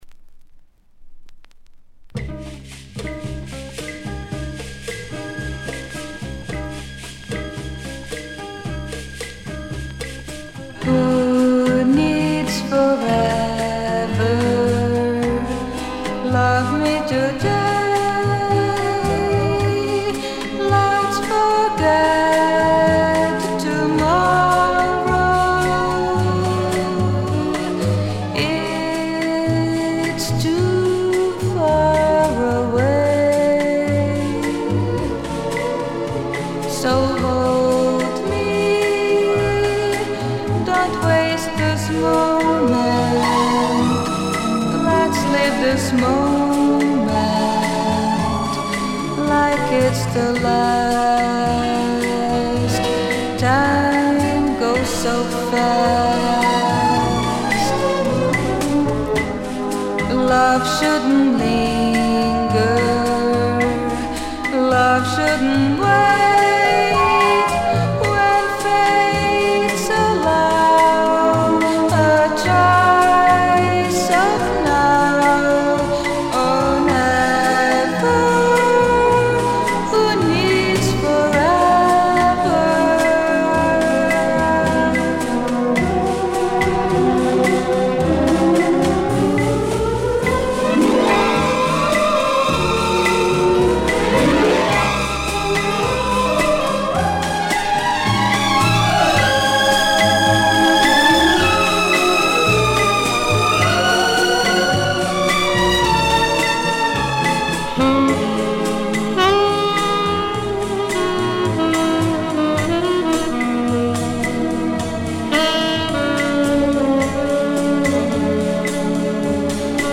Bossa Nova/O.S.T.
再生状態は小さなノイズをわずかな個所で感じる程度で再生良好です。
Side A→Side B(2:51～) 試聴はここをクリック ※実物の試聴音源を再生状態の目安にお役立てください。